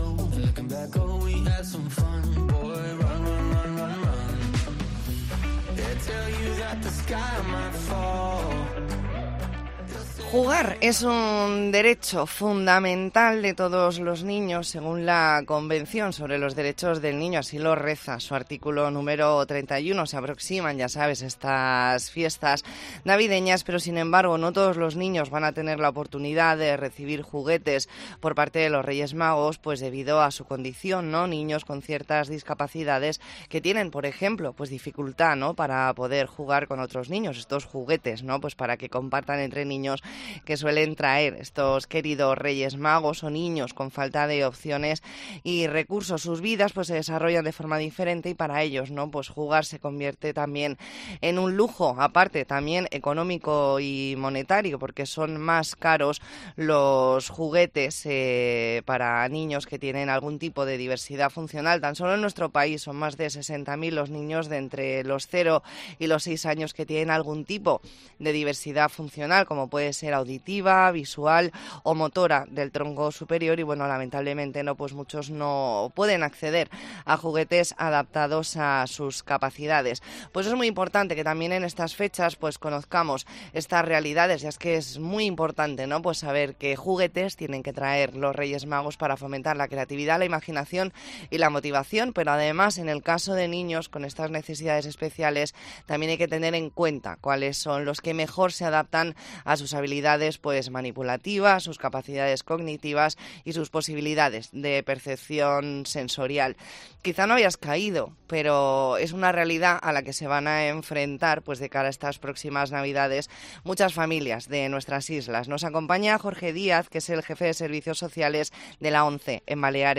Entrevista en La Mañana en COPE Más Mallorca, lunes 4 de diciembre de 2023.